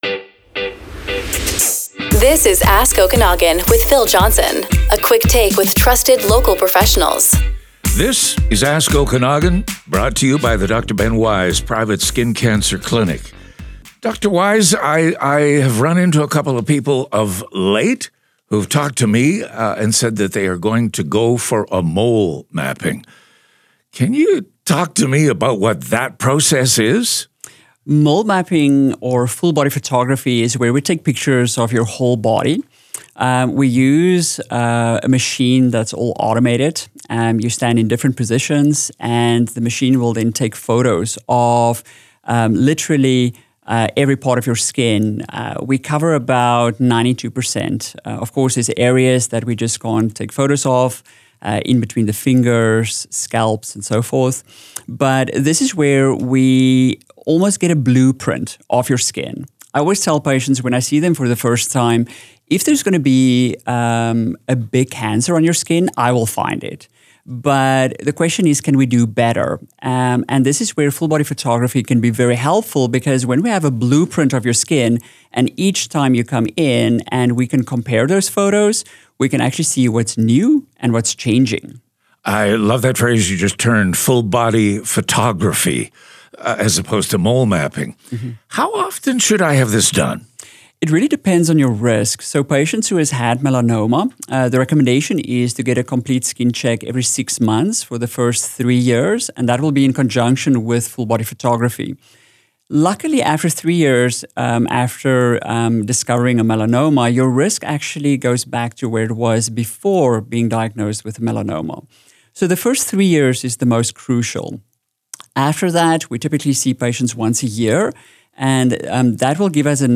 This media recording reflects a public interview and is shared for educational purposes only.